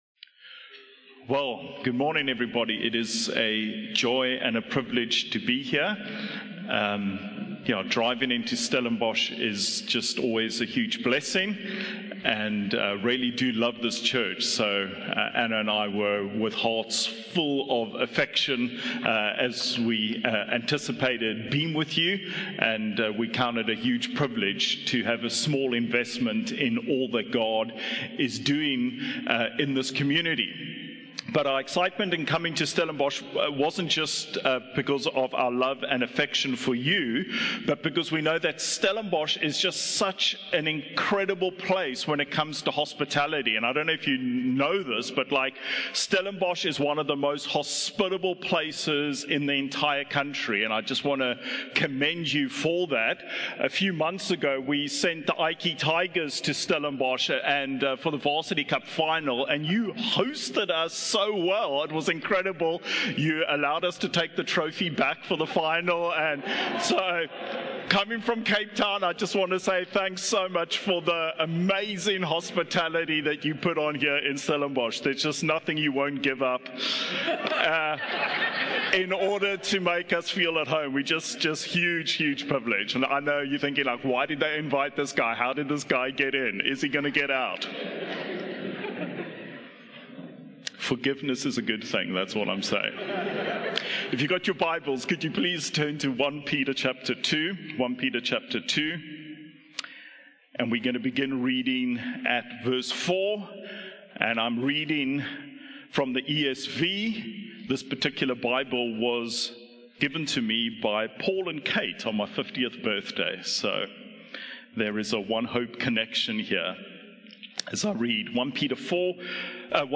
From Series: "Standalone Sermons"